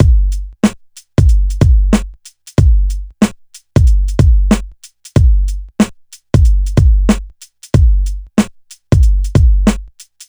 • 93 Bpm Drum Groove G Key.wav
Free drum loop - kick tuned to the G note. Loudest frequency: 724Hz
93-bpm-drum-groove-g-key-Nrk.wav